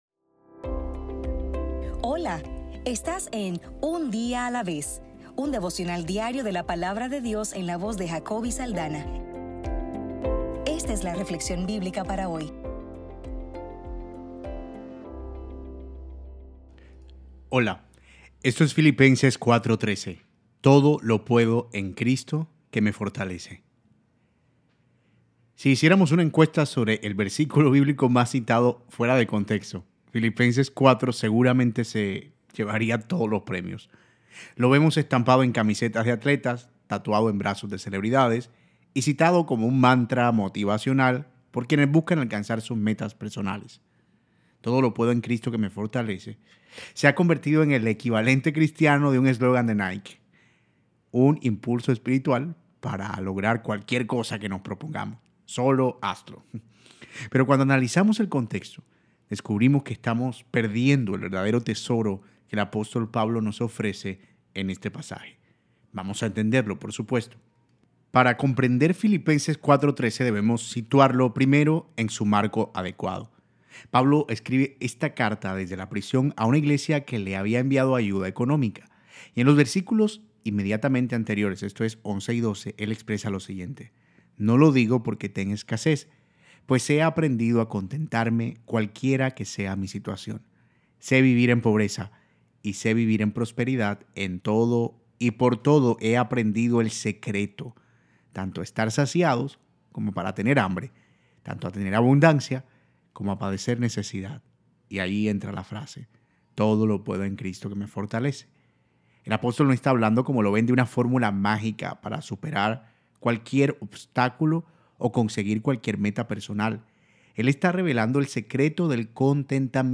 Devocional para el 30 de marzo